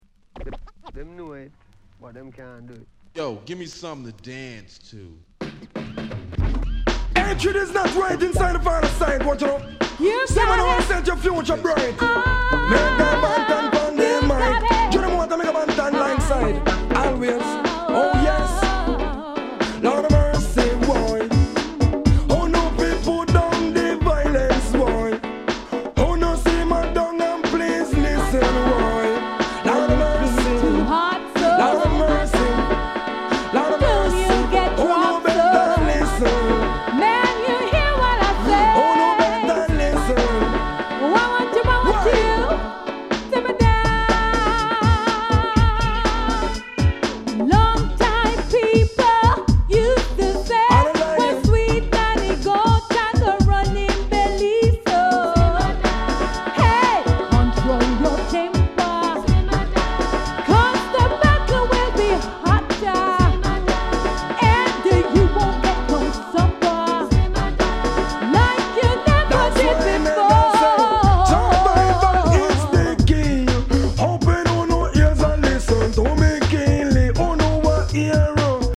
一瞬キナ臭いように感じてしまいそうですが、これはこれで良いグッドグルーヴ。